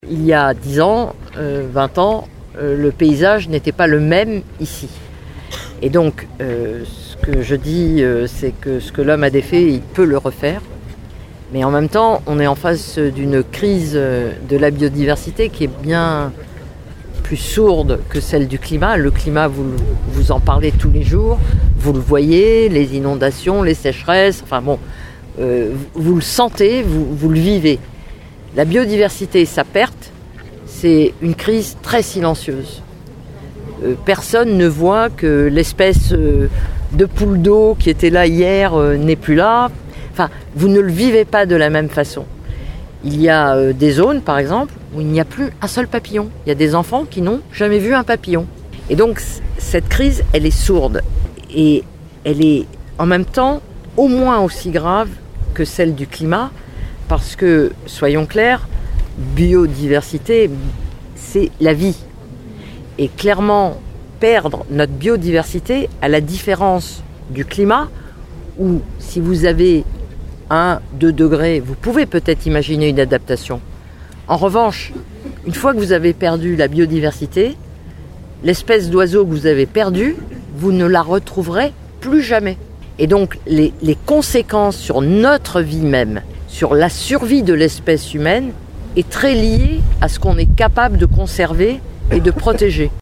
On écoute la ministre :